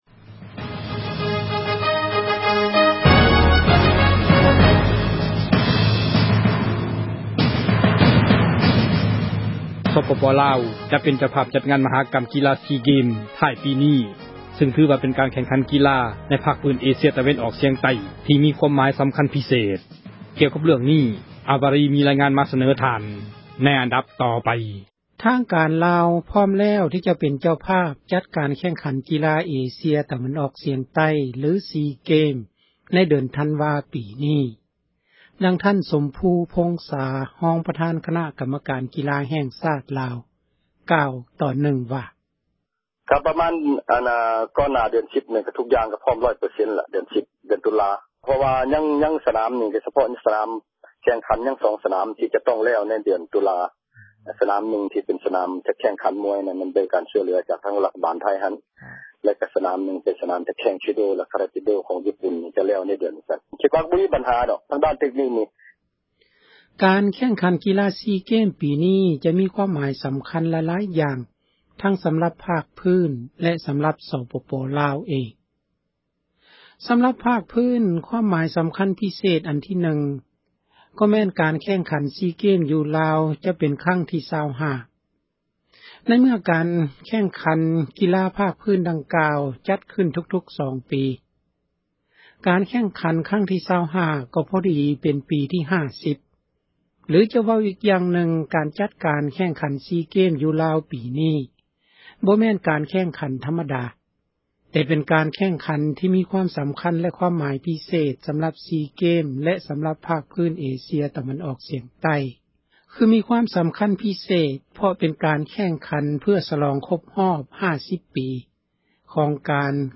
ຈະສເນີລາຍງານ ໃນອັນດັບຕໍ່ໄປ.